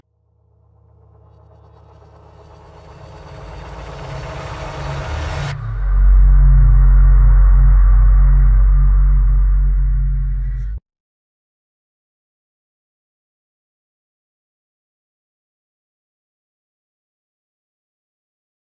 the library soundtrack